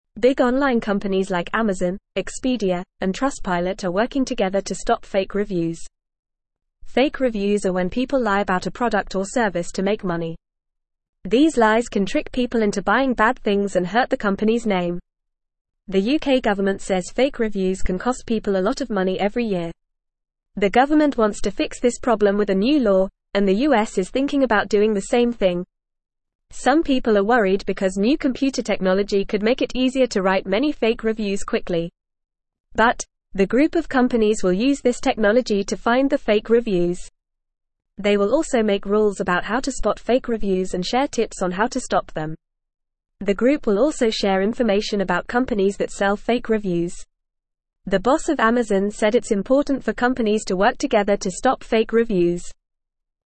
Fast
English-Newsroom-Beginner-FAST-Reading-Big-Companies-Join-Forces-to-Stop-Fake-Reviews.mp3